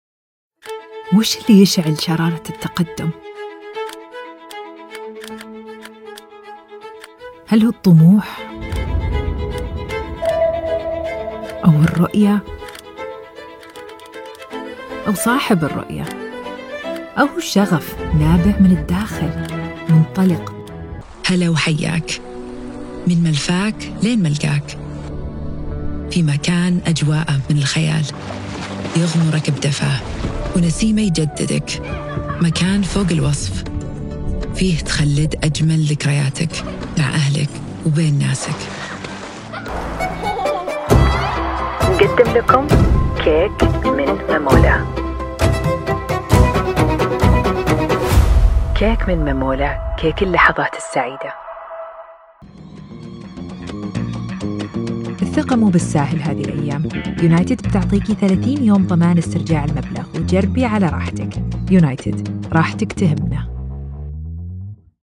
Profundo, Natural, Llamativo
Corporativo